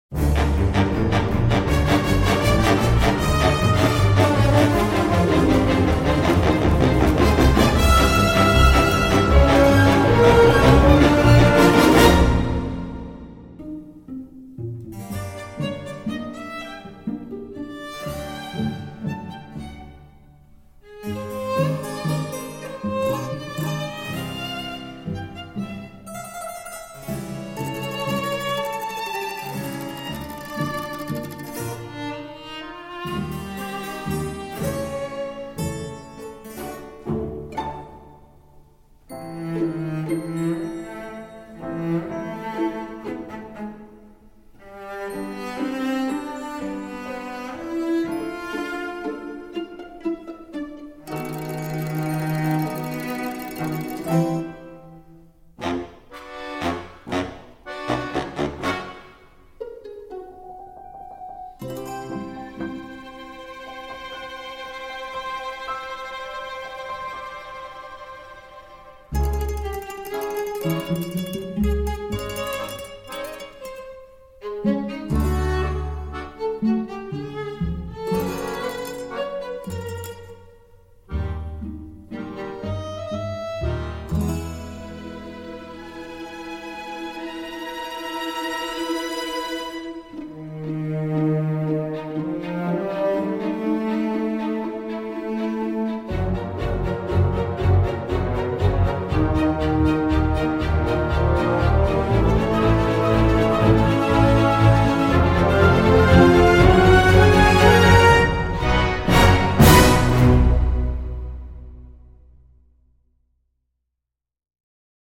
L’ensemble orchestral sonne parfois bien factice.
mickey-mousing et expérimentations gothiques marrantes.